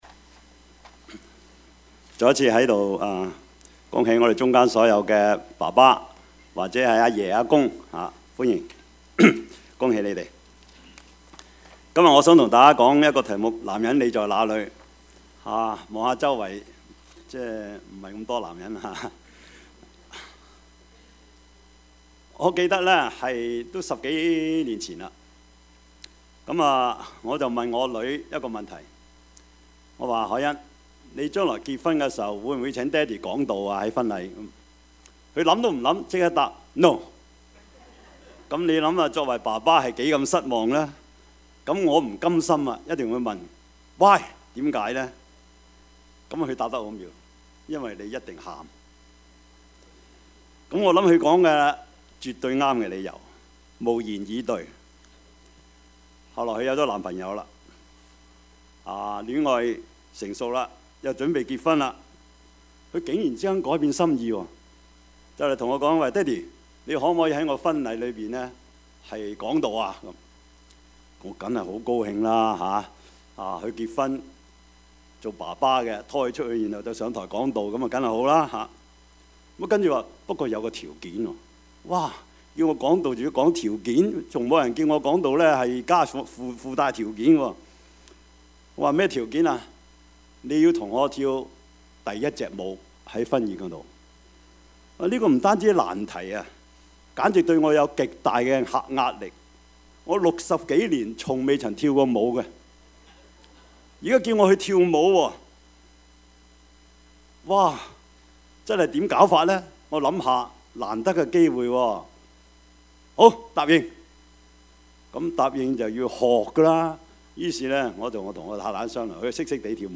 Service Type: 主日崇拜